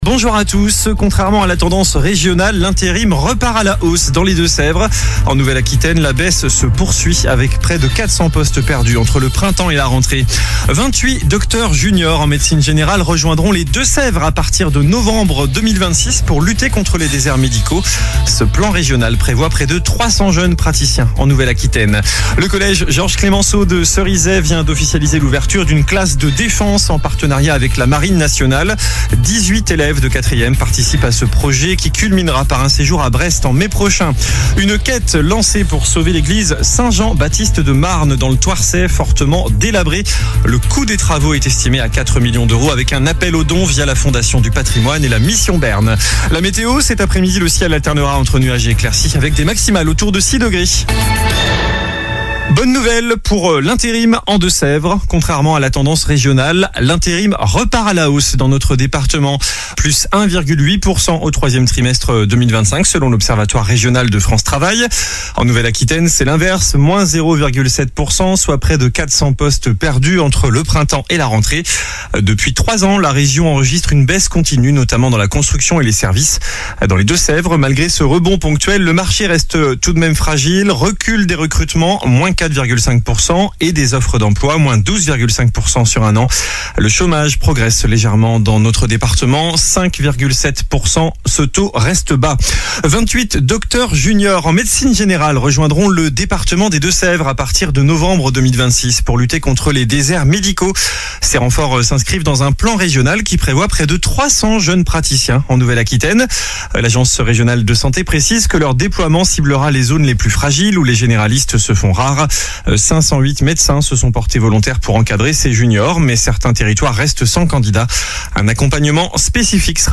Journal du lundi 29 décembre (midi)